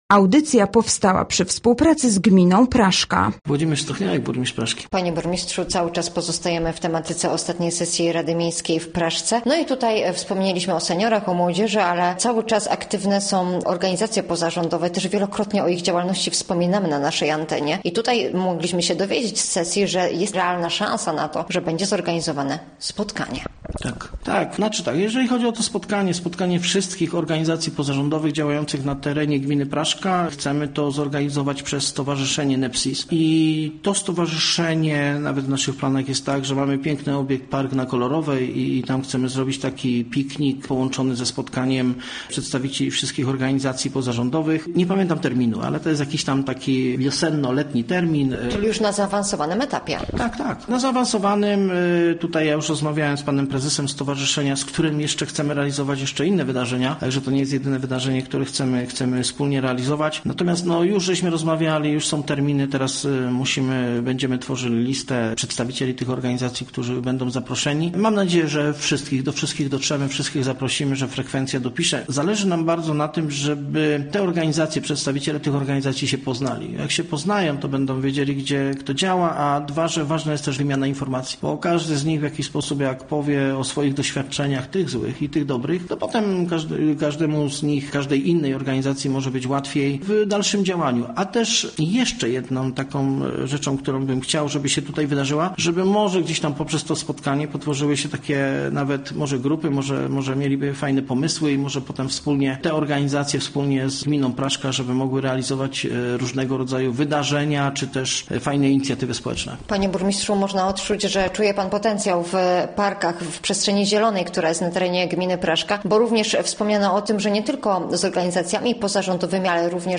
Gościem Radia ZW był burmistrz Praszki Włodzimierz Stochniałek
W gminie Praszka w tym roku będzie impreza plenerowa dla organizacji pozarządowych, planowane są również wydarzenia kulturalne na terenach zielonych miasta. O szczegółach tych inicjatyw mówił na naszej antenie burmistrz Praszki Włodzimierz Stochniałek, który w naszej rozmowie podziękował również za organizację tegorocznego finału Wielkiej Orkiestry Świątecznej Pomocy.